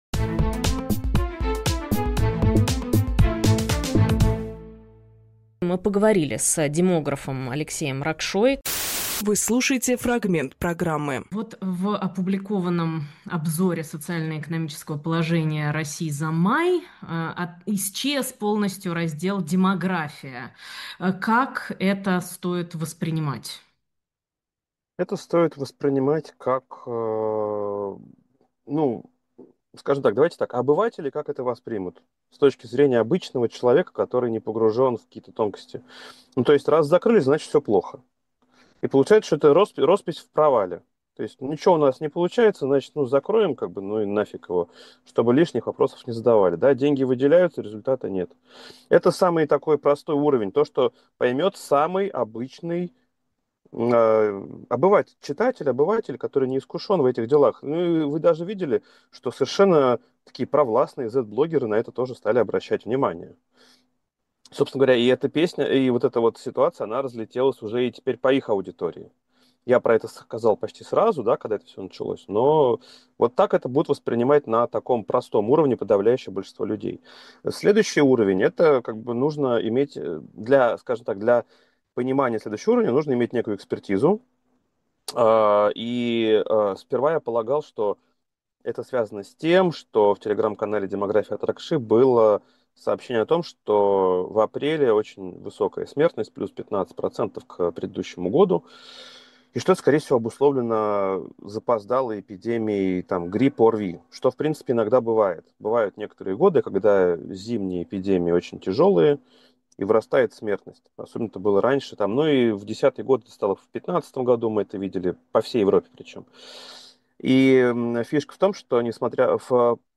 Фрагмент эфира от 06.07.25